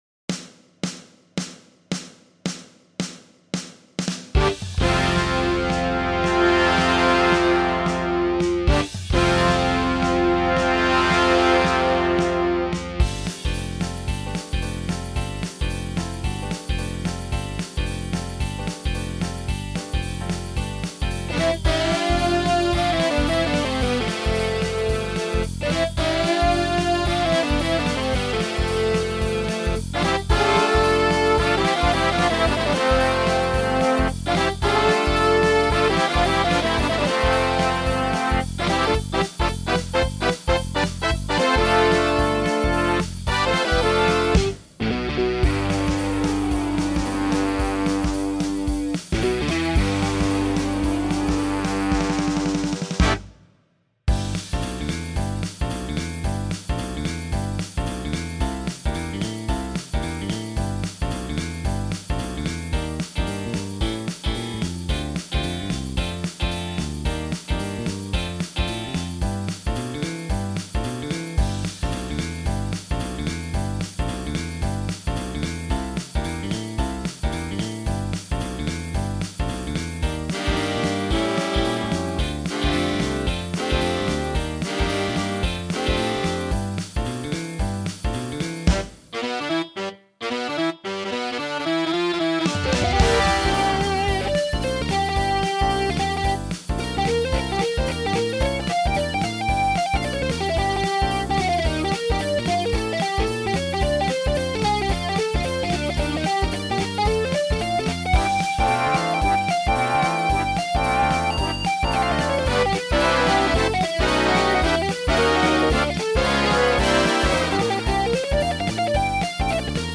Features guitar solo.
Add to cart Category: Big Band Vocal